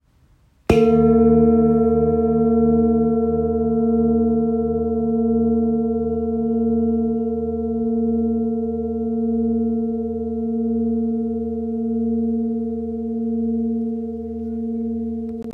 Large Etched Bowl with Seven Chakra Symbols Hindu – 40cm
The bowl measures 40cm in diameter.
Each bowl comes with a cushion and striker.